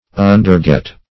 Search Result for " underget" : The Collaborative International Dictionary of English v.0.48: Underget \Un`der*get"\, v. t. To get under or beneath; also, to understand.